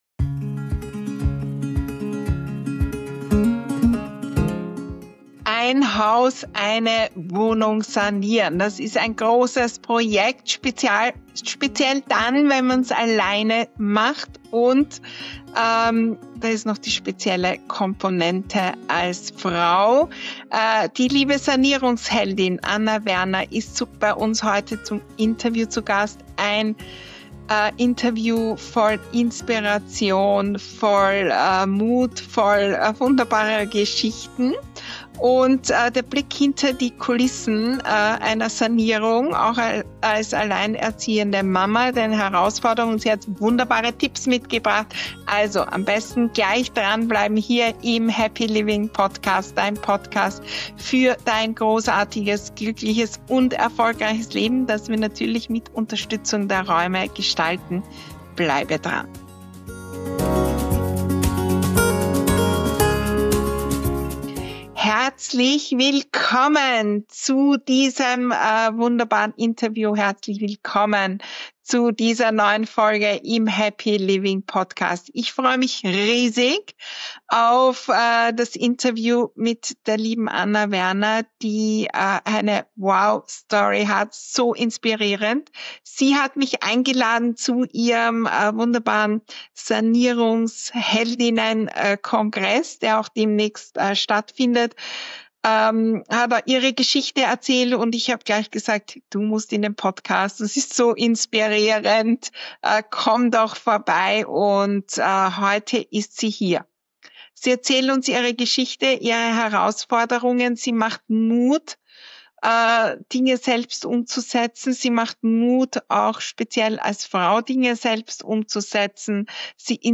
Dieses Interview ist mehr als eine Geschichte über Raumgestaltung – es ist eine Einladung zu persönlichem Wachstum, finanzieller Selbstermächtigung und echtem Female Empowerment.